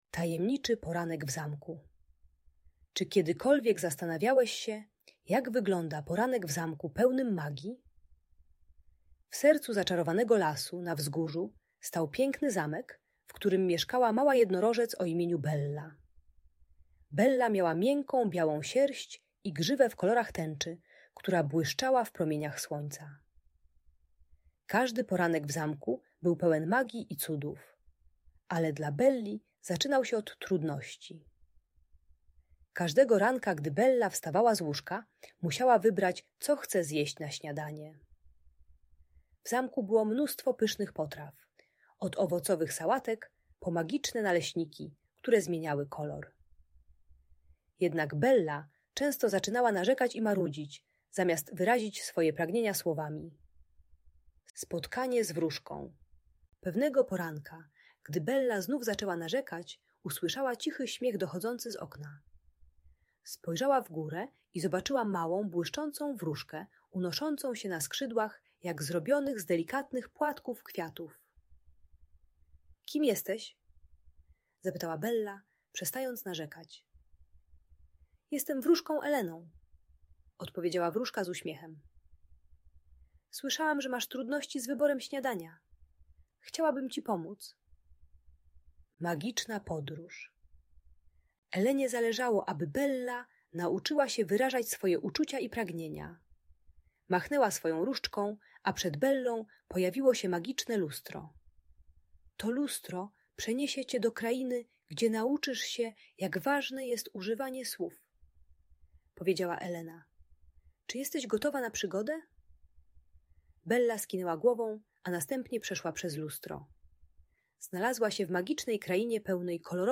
Tajemniczy Poranek w Zamku - Niepokojące zachowania | Audiobajka